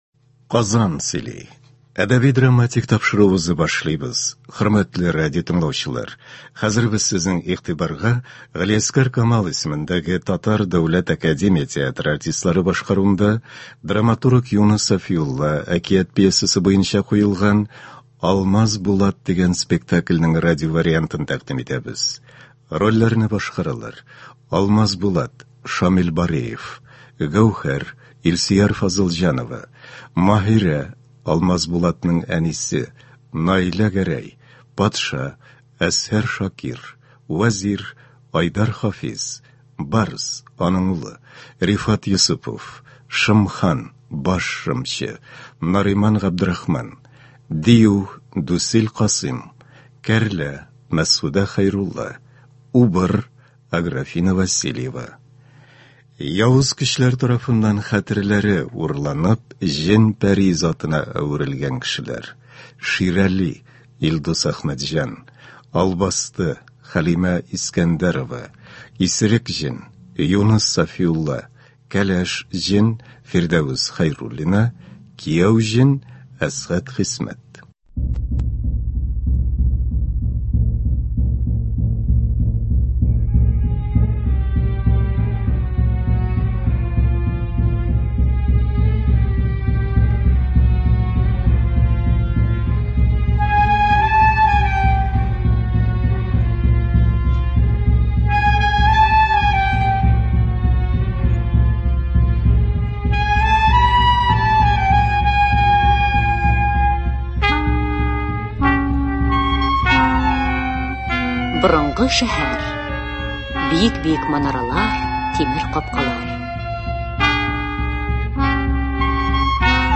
Юныс Сафиулла. “Алмазбулат”. Г.Камал ис.ТДАТ спектакле.
Кадерле дуслар, сезнең игътибарга язучы Юныс Сафиулланың «Алмазбулат» әсәре буенча эшләнгән радиоспектакль тәкъдим итәбез.